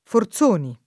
[ f or Z1 ni ]